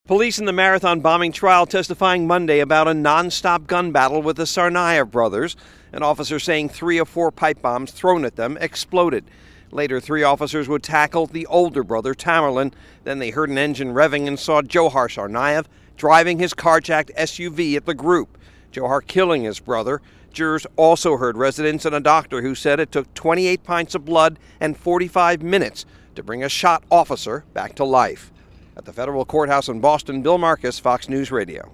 FROM THE FEDERAL COURTHOUSE IN BOSTON.